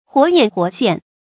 活眼活現 注音： ㄏㄨㄛˊ ㄧㄢˇ ㄏㄨㄛˊ ㄒㄧㄢˋ 讀音讀法： 意思解釋： 同「活龍活現」。